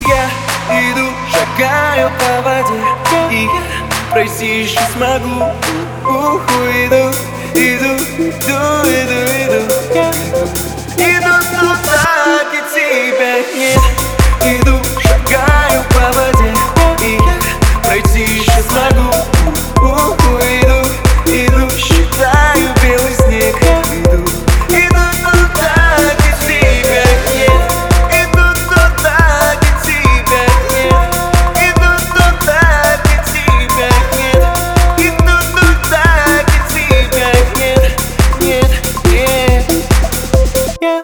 • Качество: 320, Stereo
поп
мужской вокал
Жанр: Хип-хоп/рэп